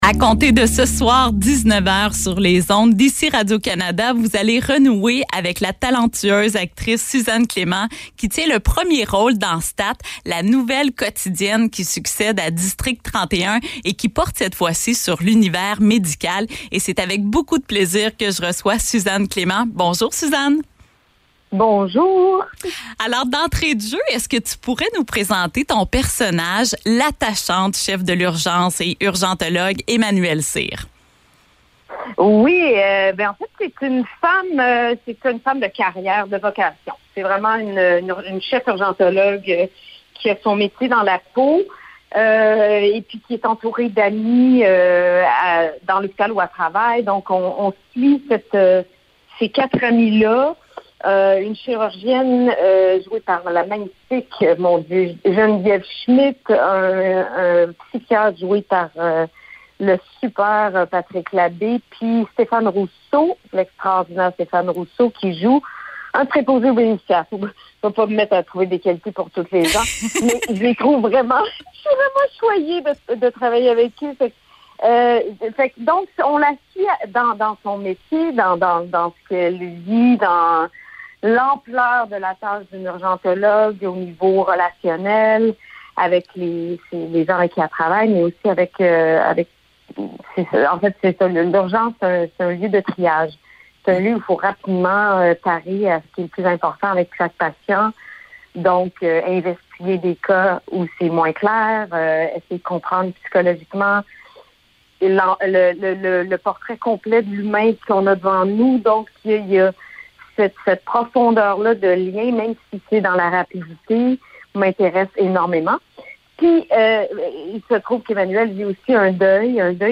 Entrevue avec Suzanne Clément :